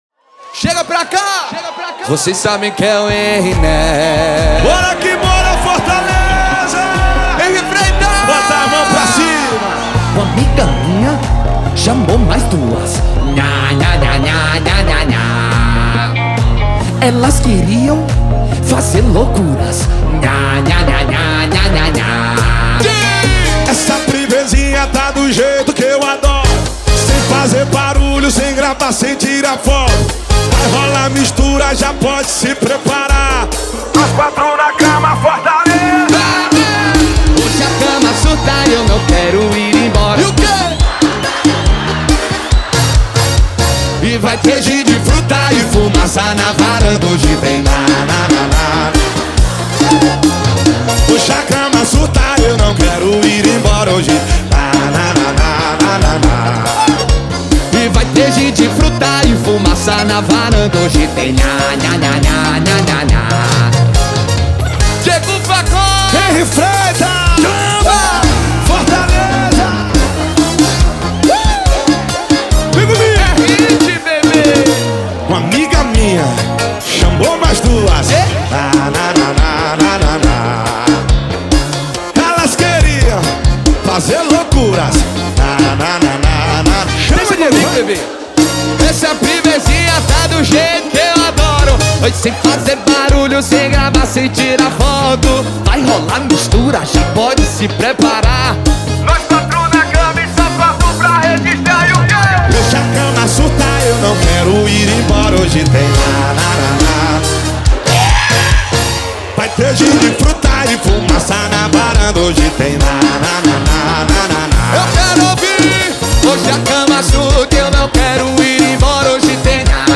2024-02-14 18:37:05 Gênero: Forró Views